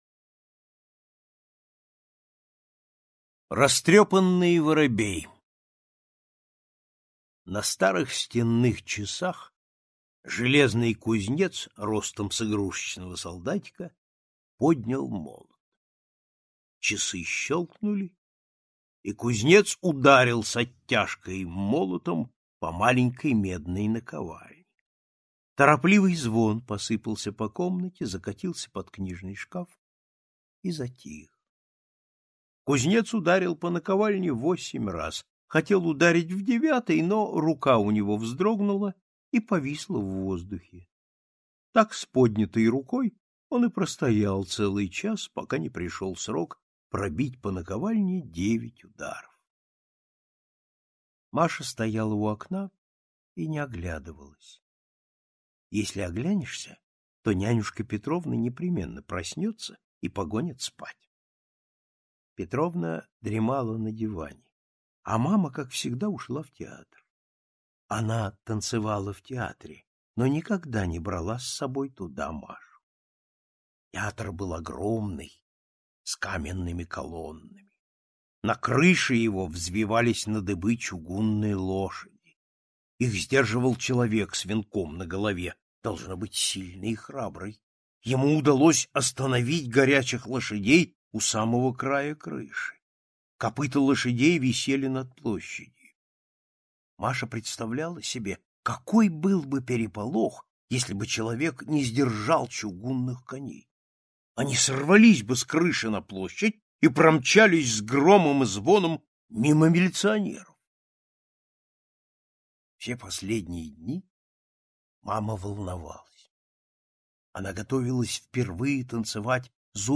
Слушайте Растрепанный воробей - аудио рассказ Паустовского К. Как старая ворона украла у балерины брошь, которая была той очень дорога.